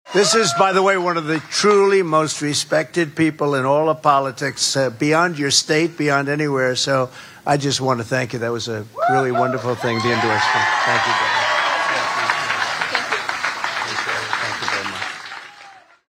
Bird made the announcement just before introducing Trump to a crowd in Adel this (Monday) afternoon.